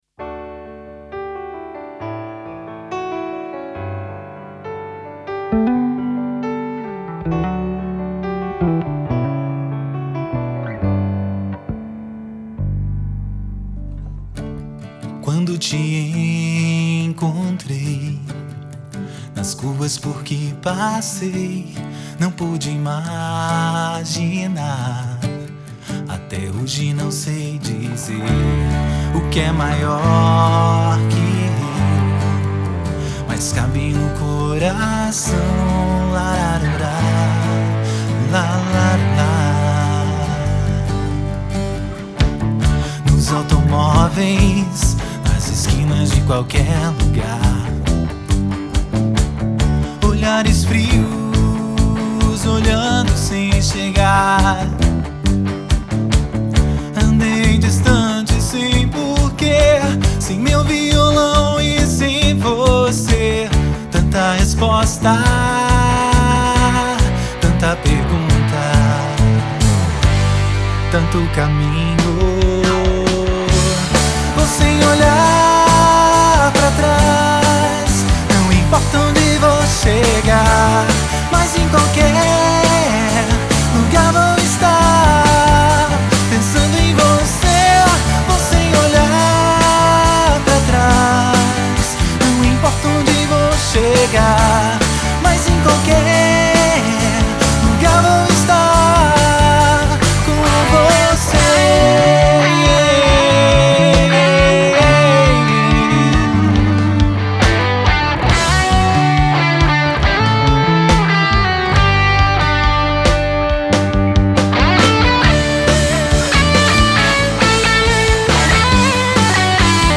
EstiloFolk